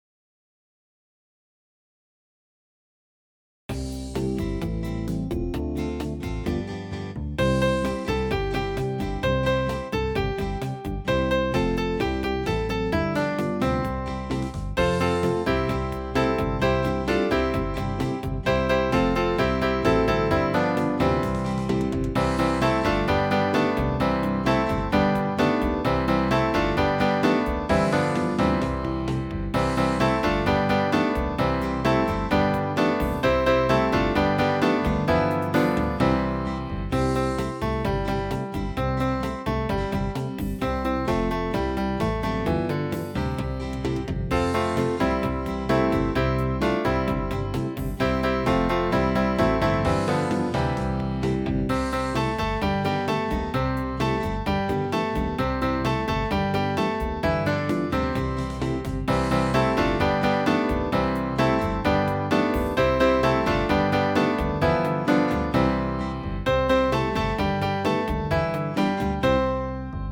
Traditional
8-beat intro.
This arrangement has some additional chords.